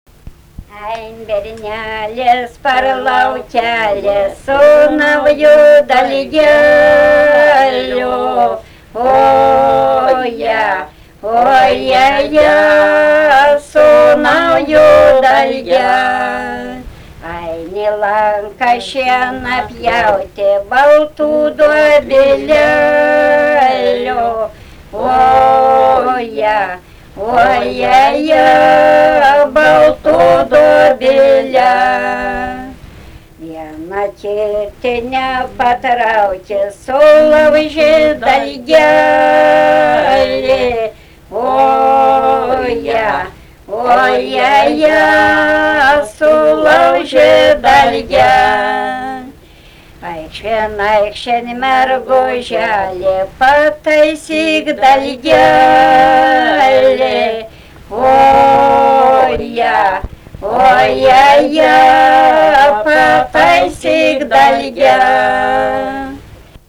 daina
Žaduvėnai
vokalinis
2 balsai